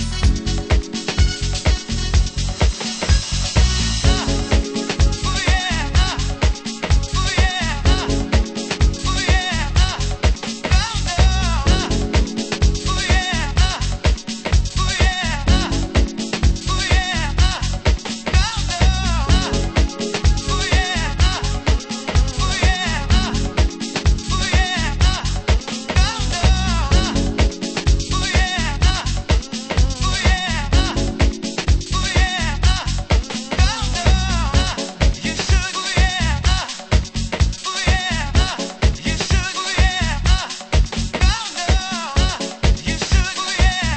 Main Vocal